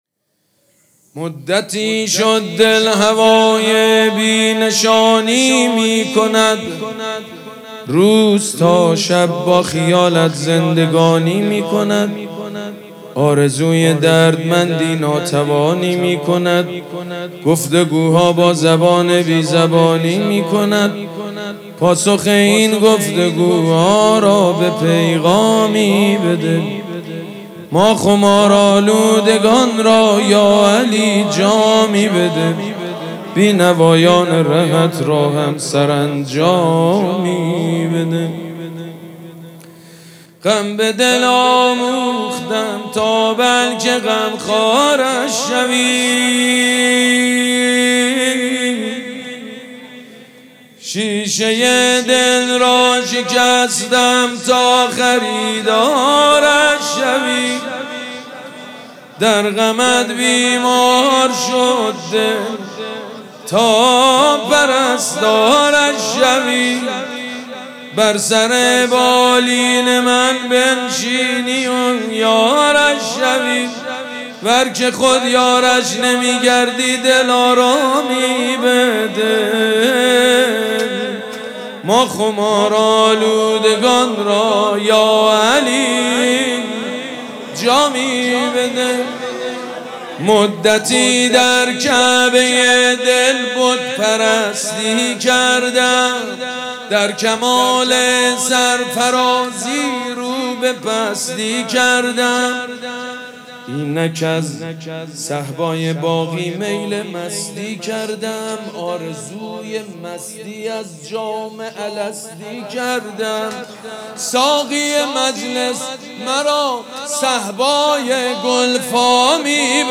شعر خوانی
حاج سید مجید بنی فاطمه
جشن عید غدیرخم